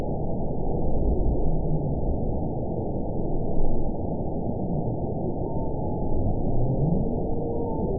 event 921008 date 04/24/24 time 01:26:55 GMT (1 year, 1 month ago) score 9.24 location TSS-AB07 detected by nrw target species NRW annotations +NRW Spectrogram: Frequency (kHz) vs. Time (s) audio not available .wav